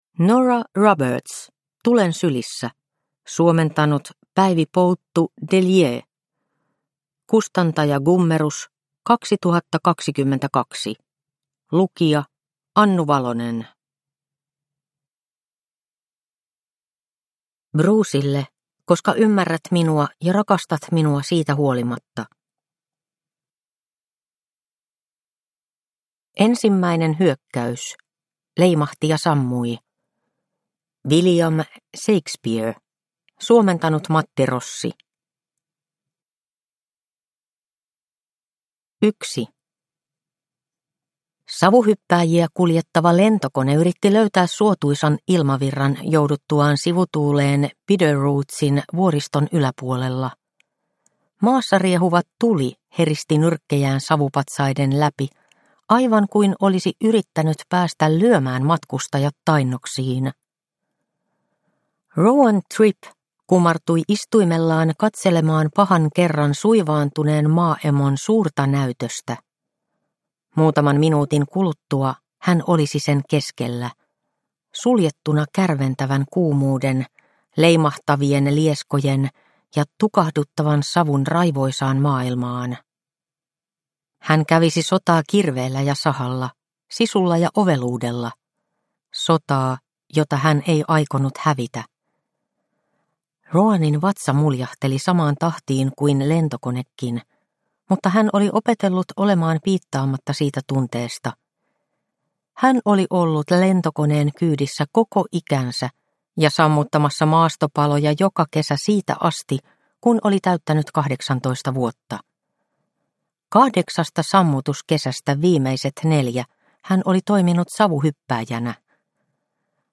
Tulen sylissä – Ljudbok – Laddas ner